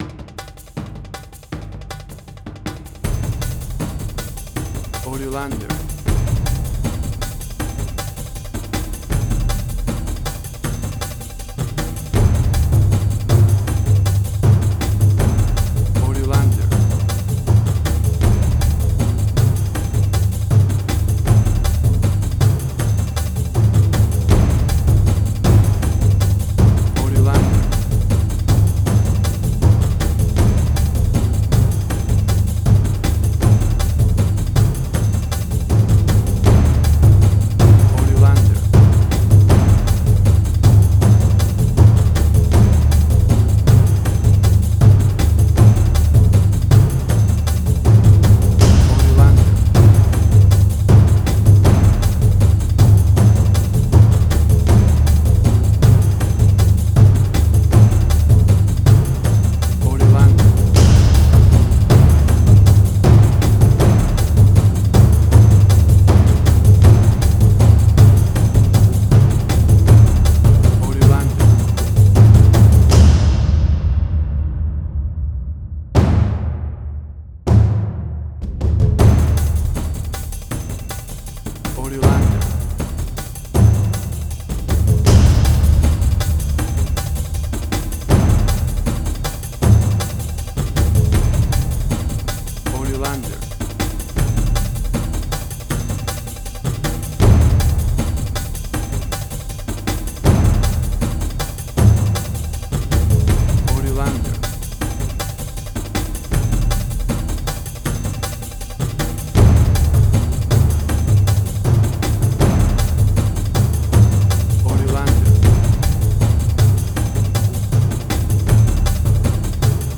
Exotic and world music!
Tempo (BPM): 158